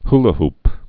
(hlə-hp)